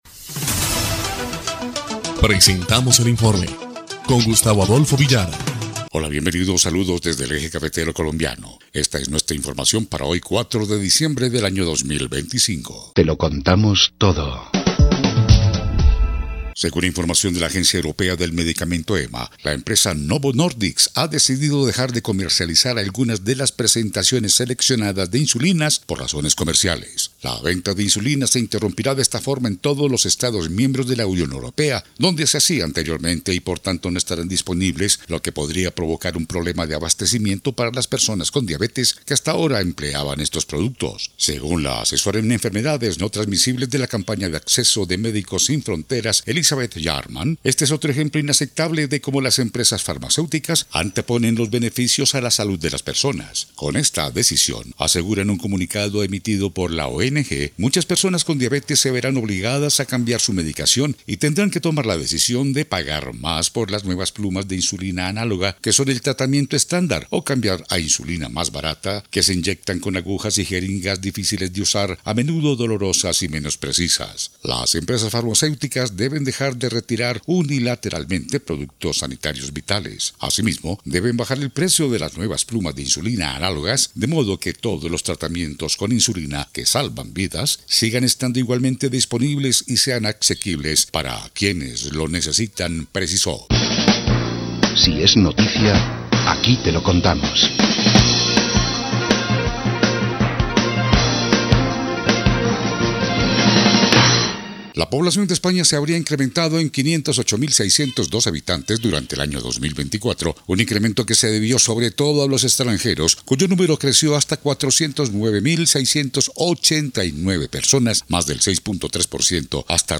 EL INFORME 2° Clip de Noticias del 4 de diciembre de 2025